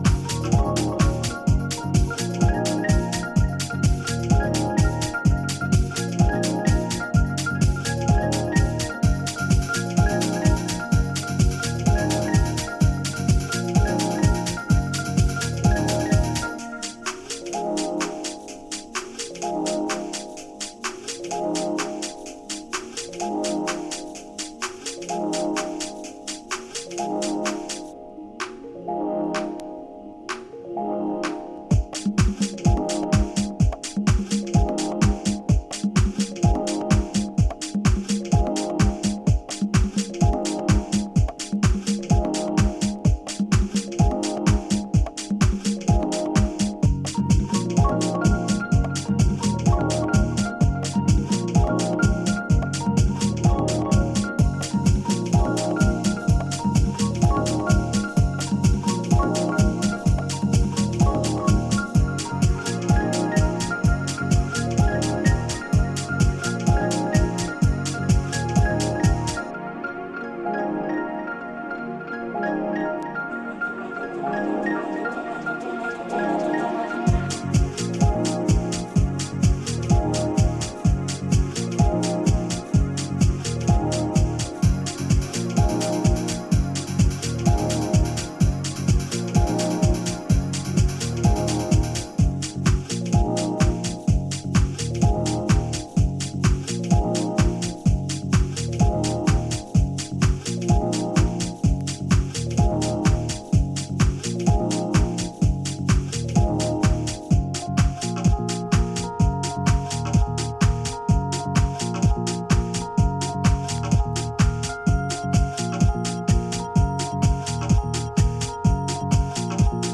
Extended bumper deep EP